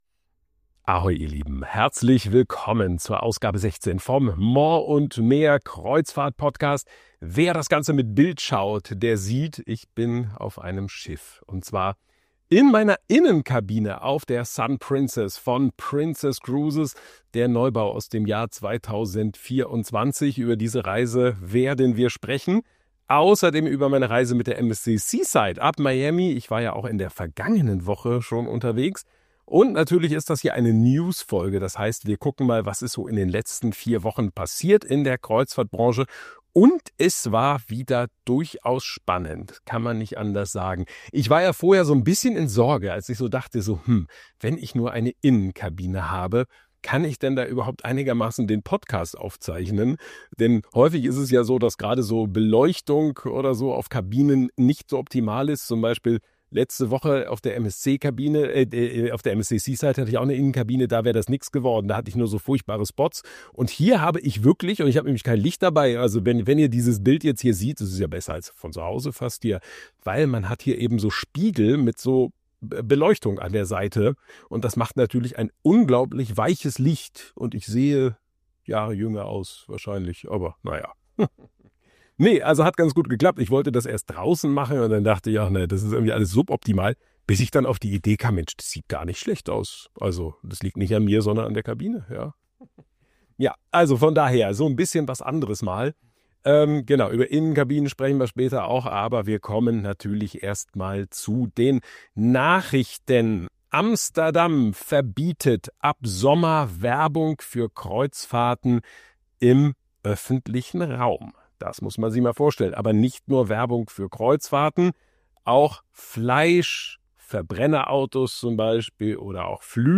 In Folge 16 des Morr & Meer Kreuzfahrt-Podcasts melde ich mich direkt von Bord der Sun Princess, dem Neubau von Princess Cruises aus dem Jahr 2024.